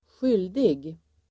Uttal: [²sj'yl:dig]